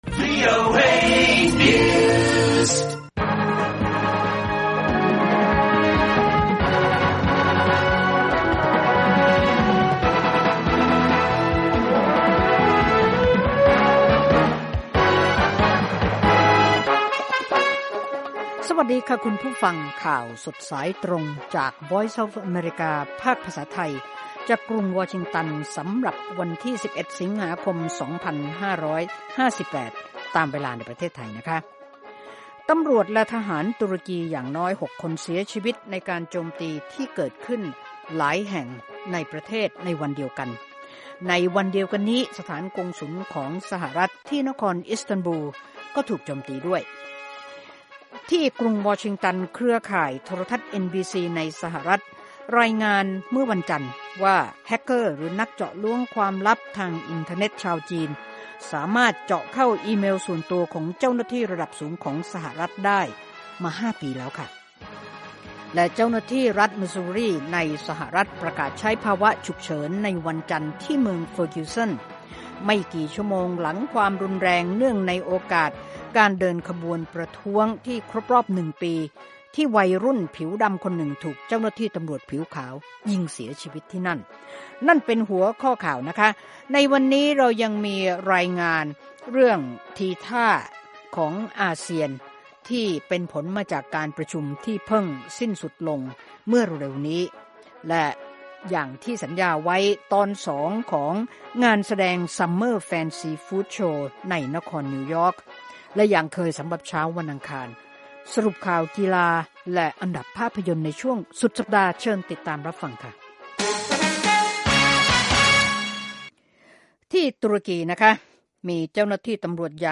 ข่าวสดสายตรงจากวีโอเอ ภาคภาษาไทย 6:30 – 7:00 น. 11 สิงหาคม พ.ศ.2558